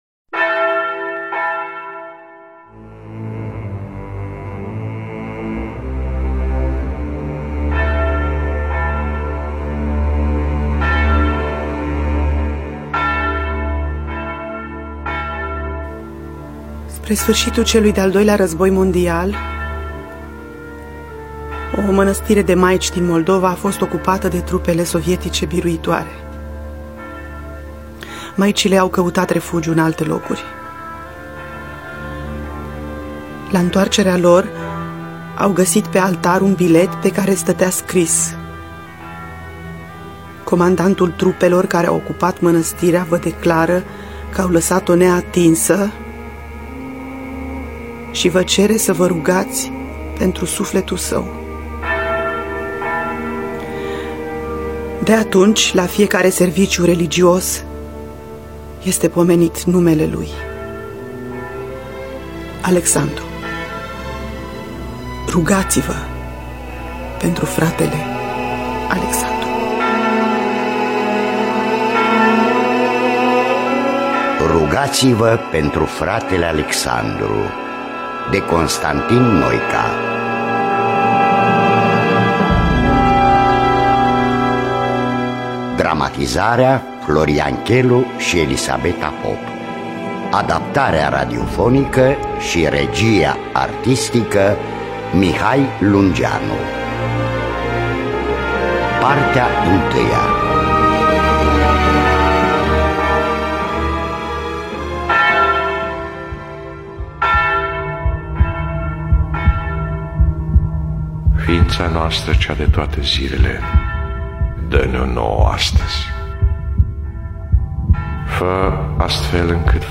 Biografii, Memorii: Constantin Noica – Rugati-va Pentru Fratele Alexandru (2005) – Partea 1 – Teatru Radiofonic Online